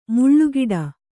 ♪ muḷḷu giḍa